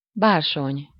Ääntäminen
Ääntäminen France: IPA: [və.luʁ] Tuntematon aksentti: IPA: /z/ IPA: /t/ Haettu sana löytyi näillä lähdekielillä: ranska Käännös Ääninäyte Substantiivit 1. bársony Muut/tuntemattomat 2. velúr Suku: m .